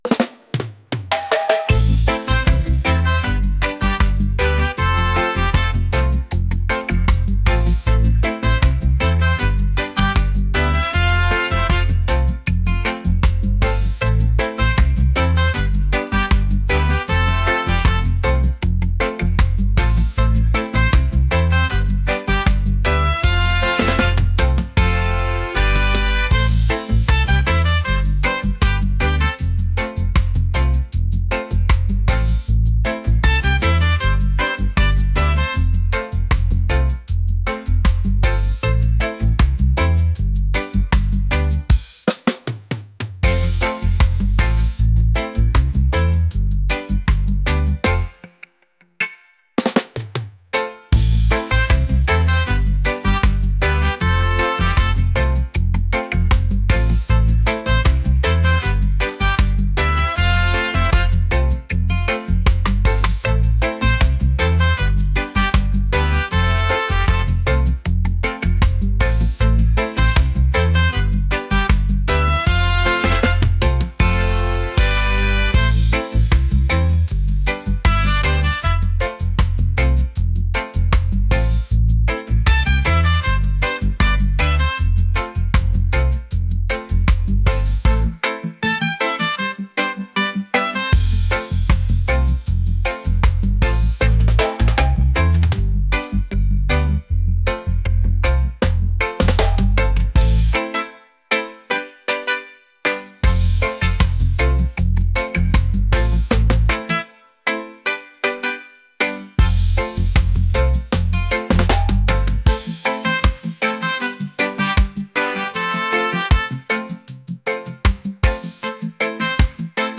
lively | reggae